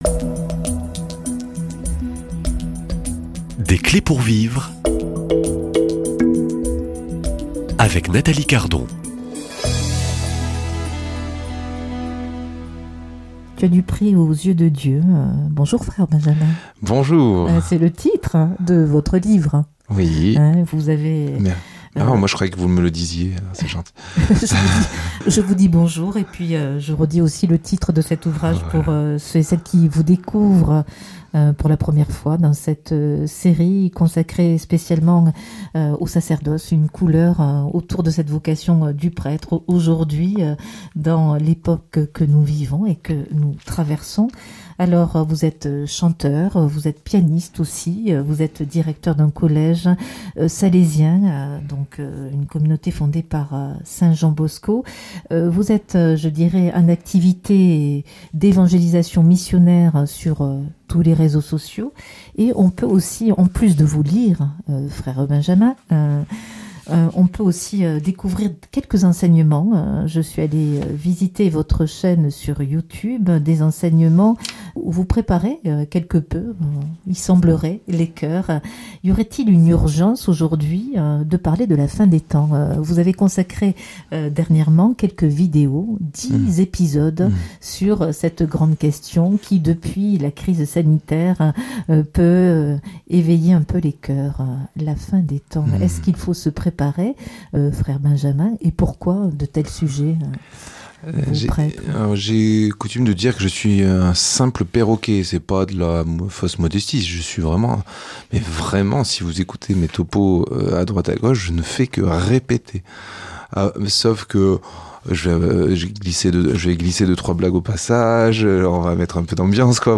Invité